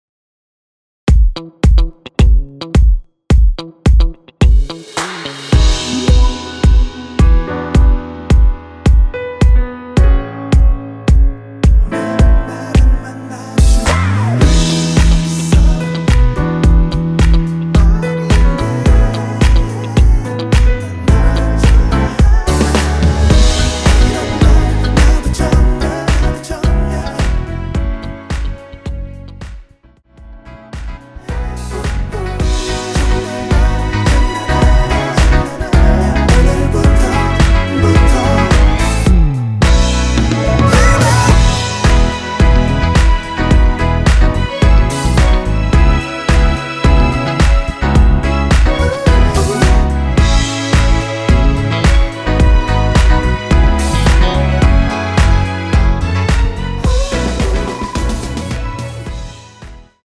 원키 코러스 포함된 MR입니다.(미리듣기 확인)
앞부분30초, 뒷부분30초씩 편집해서 올려 드리고 있습니다.
중간에 음이 끈어지고 다시 나오는 이유는